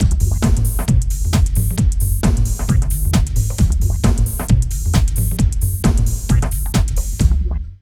80 LOOP   -R.wav